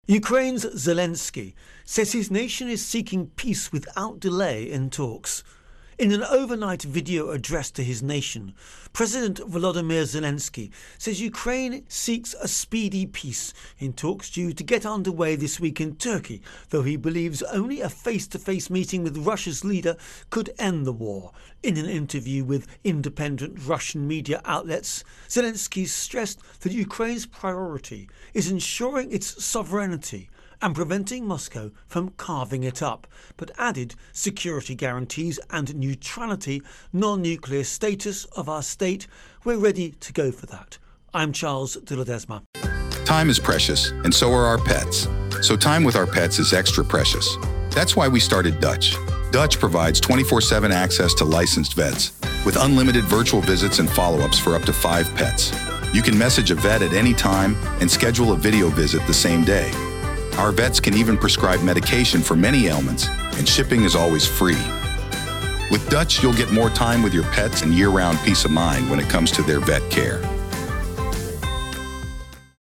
Russia-Ukraine-War Intro and Voicer